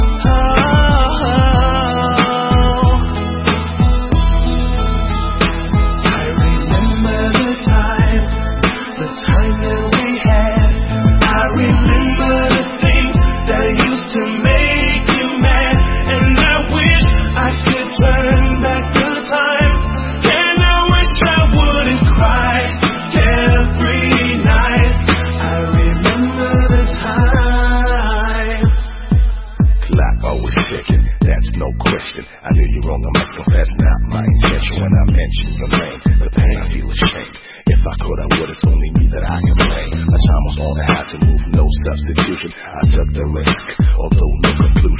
Зона обмена: Музыка | Спокойная
аж спать от неё хочется